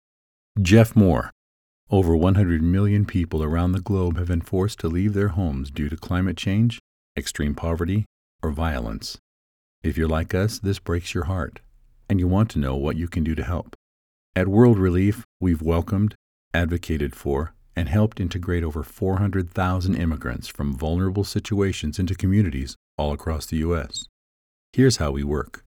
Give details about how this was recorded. World Relief (with slate):